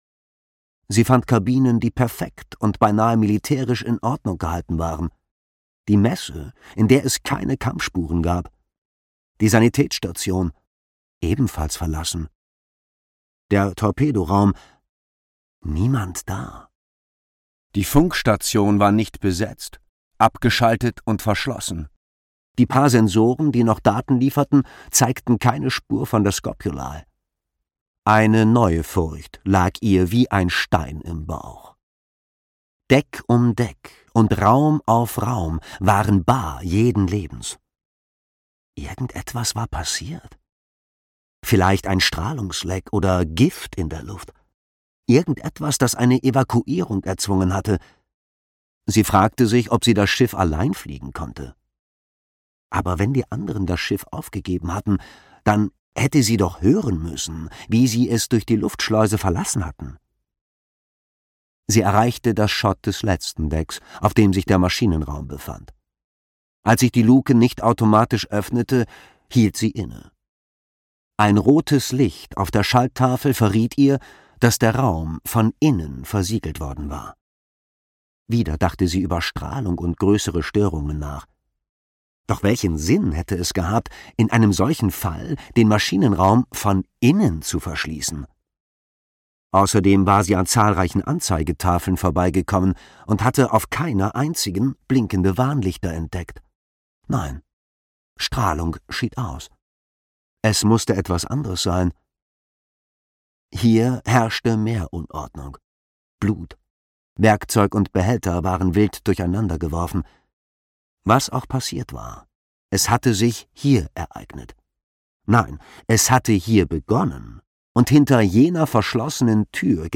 Leviathan erwacht (DE) audiokniha
Ukázka z knihy